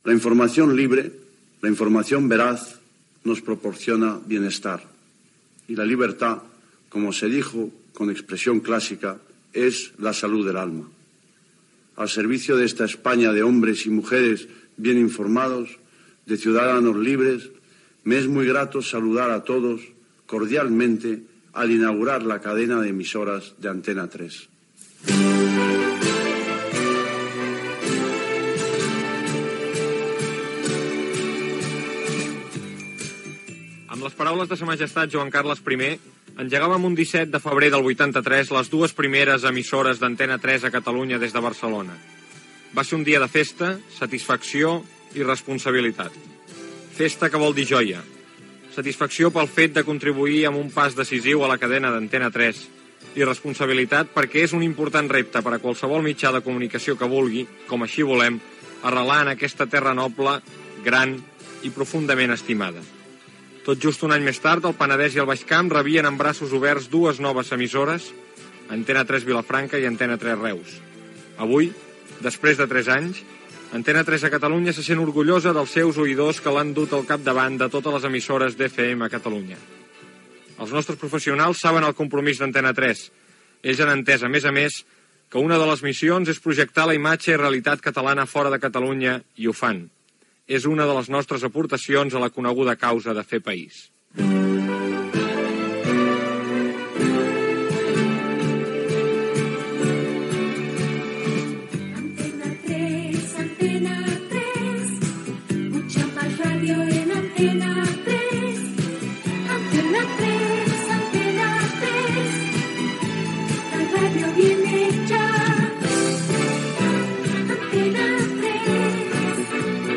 Paraules del rei Juan Carlos I, a l'any 1983, comentari sobre els primers tres anys d'Antena 3 Radio a Catalunya, indicatiu de la ràdio
FM